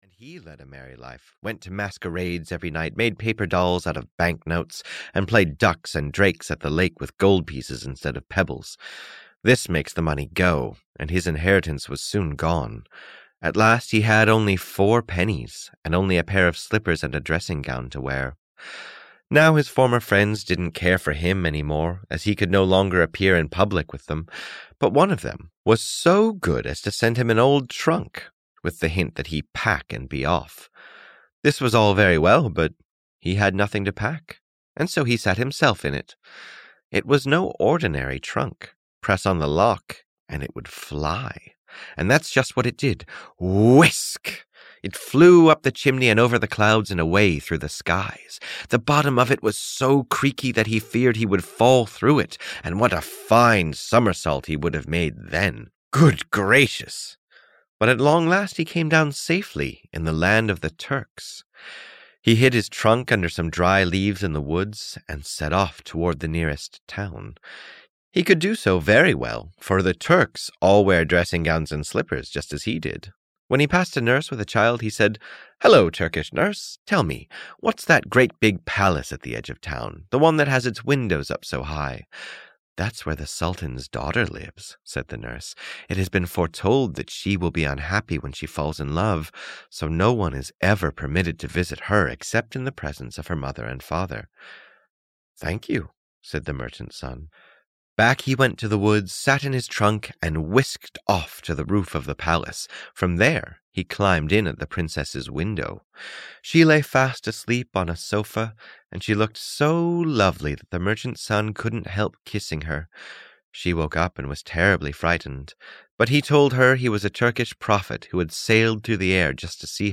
The Flying Trunk (EN) audiokniha
Ukázka z knihy